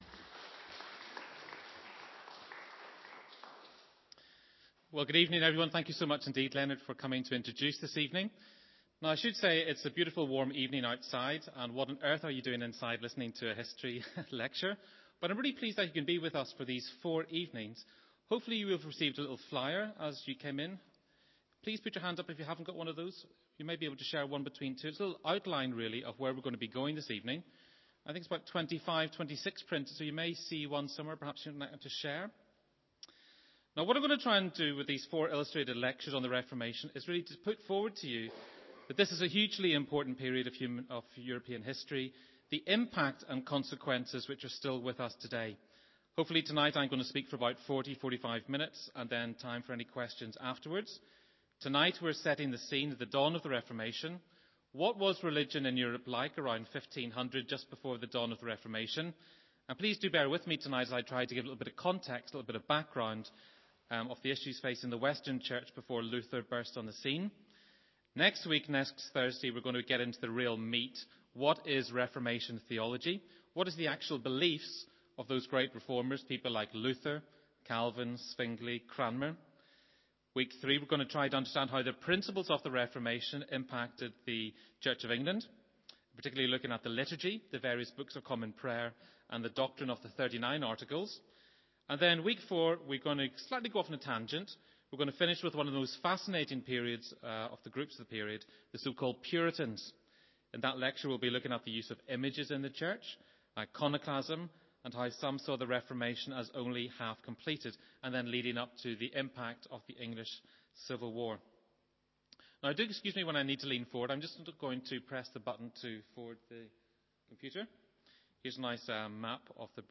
Reformation Lecture Part 1